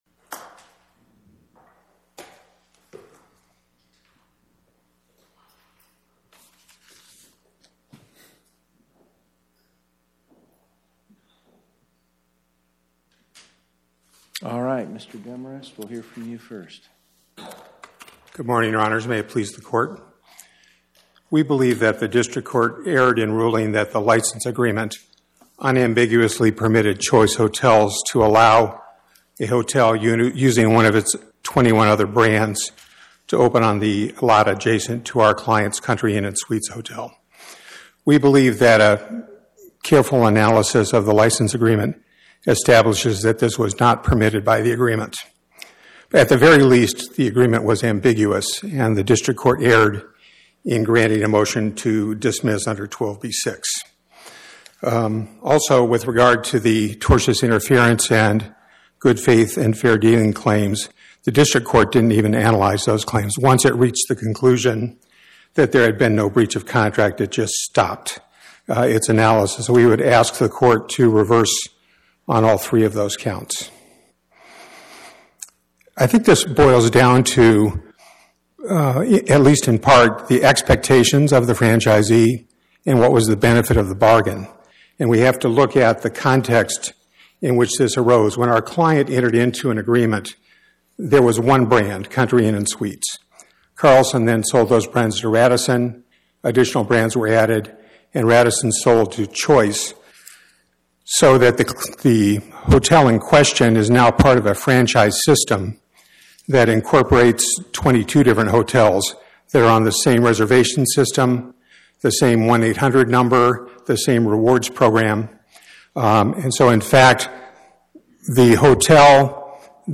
My Sentiment & Notes 25-1618: T&T Management, Inc. vs Choice Hotels Int'l Podcast: Oral Arguments from the Eighth Circuit U.S. Court of Appeals Published On: Wed Feb 11 2026 Description: Oral argument argued before the Eighth Circuit U.S. Court of Appeals on or about 02/11/2026